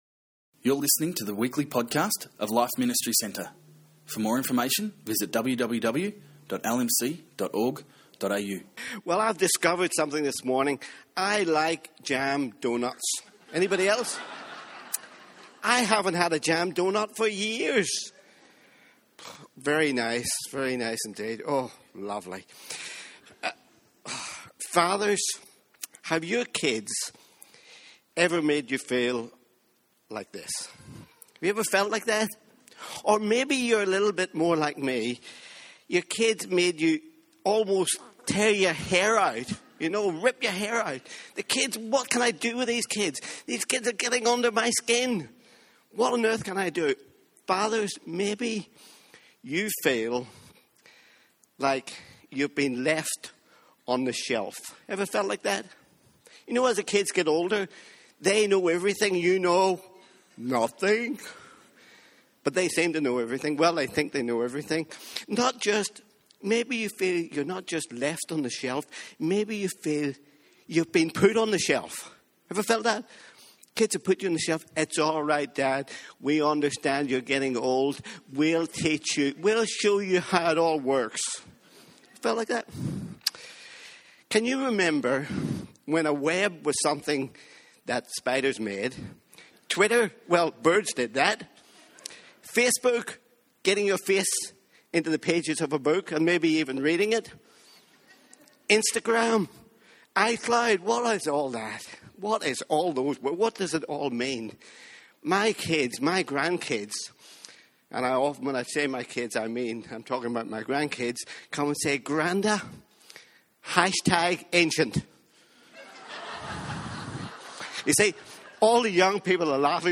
On this special Father's Day service